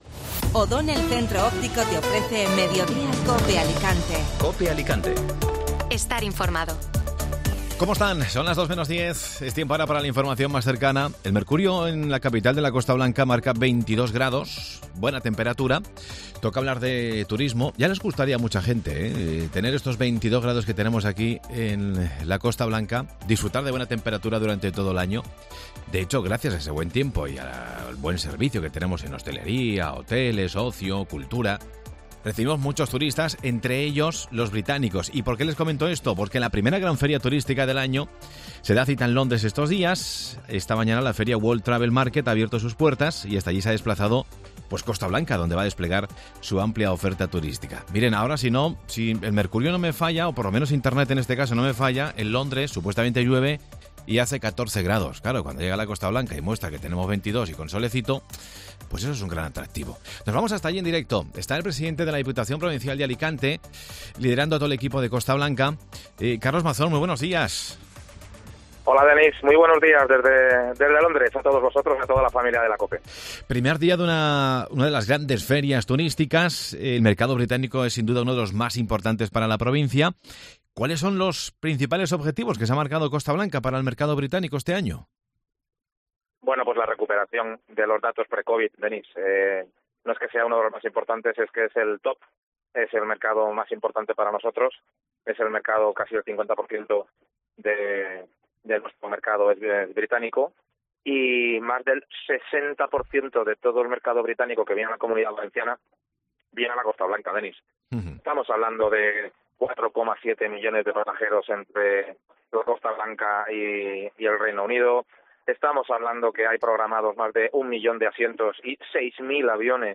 Escucha la entrevista a Carlos Mazón desde la World Travel Market